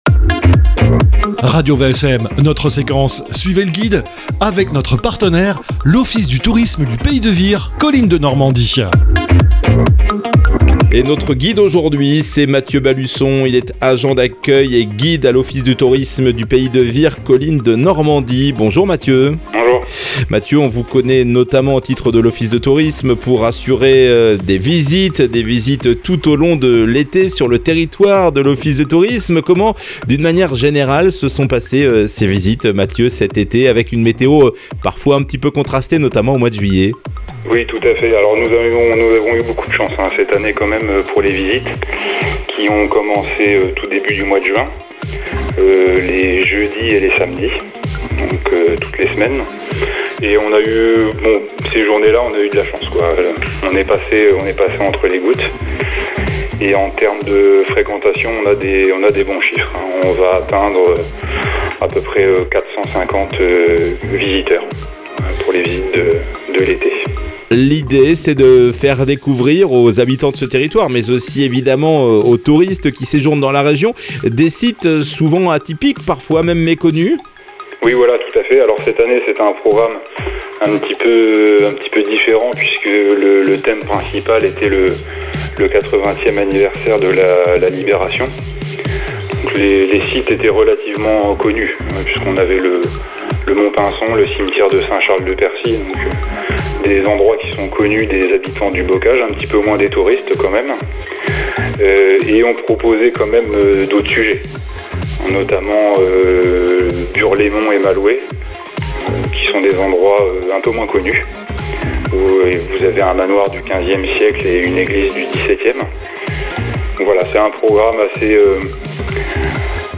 Suivez Le Guide - Interview